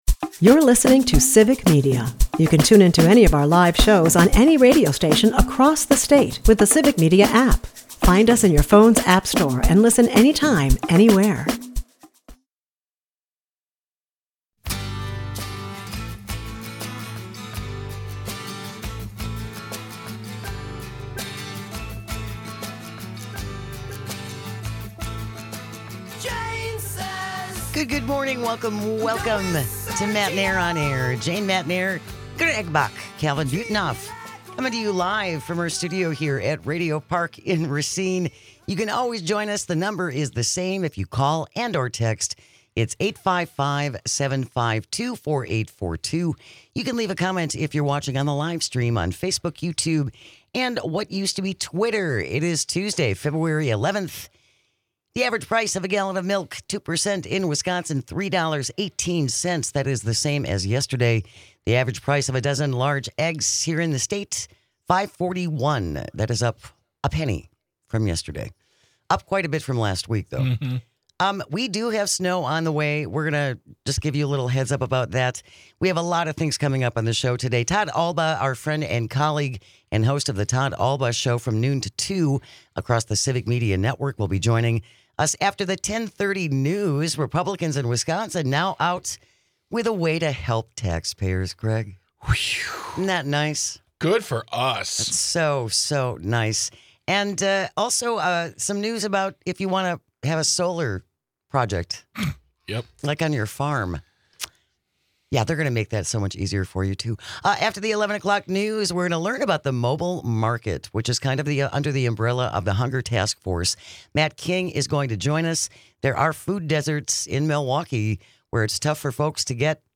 They cover all the news that affects you with humor and a unique perspective.